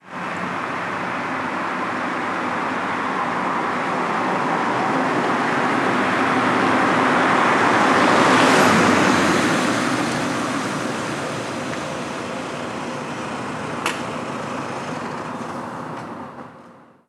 Coche Golf llegando y parando
coche
Sonidos: Transportes